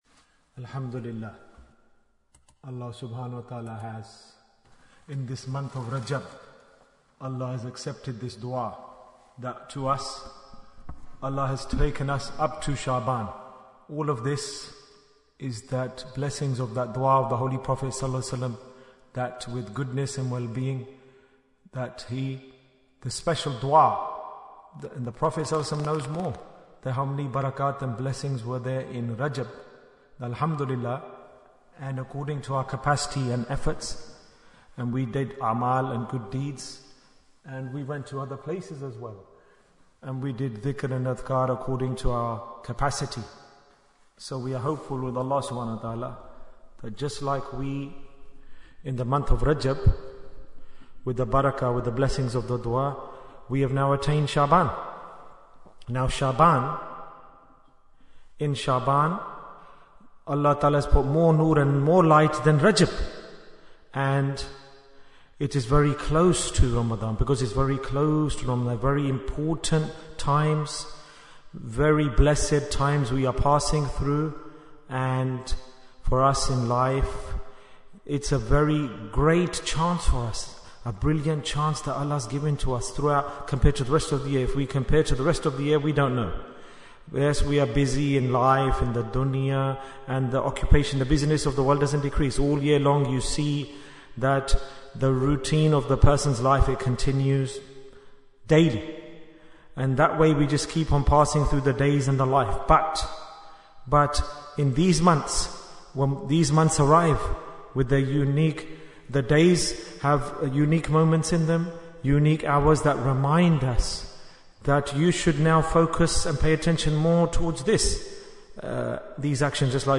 Talk before Dhikr 1714 minutes1st February, 2025